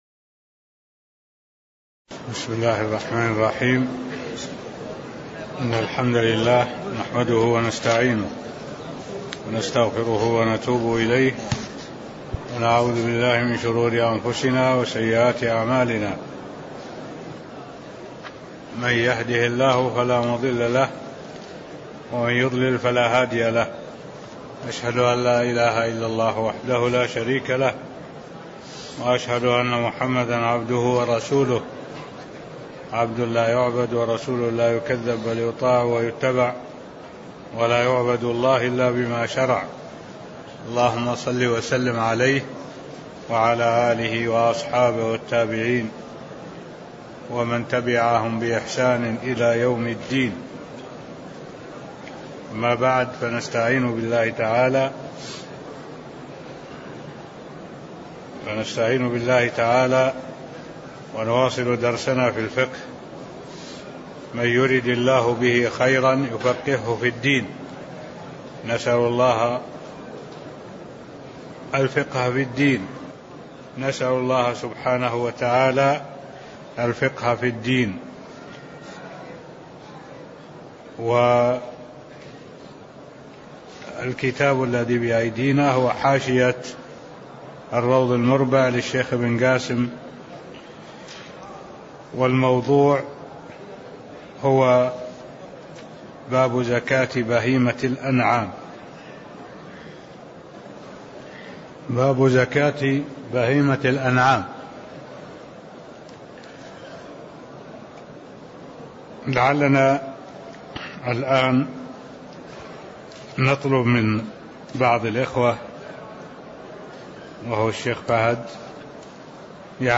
تاريخ النشر ٢٢ ربيع الأول ١٤٢٩ هـ المكان: المسجد النبوي الشيخ: معالي الشيخ الدكتور صالح بن عبد الله العبود معالي الشيخ الدكتور صالح بن عبد الله العبود زكاة بهيمة الأنعام (006)